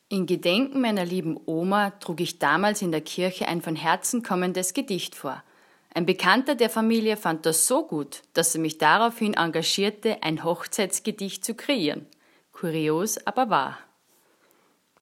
Vor kurzem ist Life Radio auf mich zugekommen und hat mich zu meinen Hochzeitsgedichten befragt.